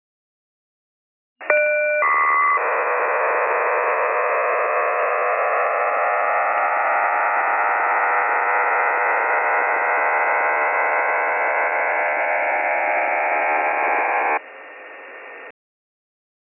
Yugoslavian OFDM-based system, k = 7/15
Нижняя граница (Low Range) ~420-460 Гц
Частота манипуляции (Baud Rate) ~75 Гц, в канале
Несущих / спектральных полос (Count of Carriers) 20 хорошо различимых частот манипуляции
Разнос между несущими / полосами (Step between carriers) 110 Гц
Пилот-тоны: разнос, частоты (Pilot tone(s)) 3 не модулированных старт тона, с разносом 880 Гц и длительностью ~500 мс
Модуляция, в которой сигнал принят (RX mode) SSB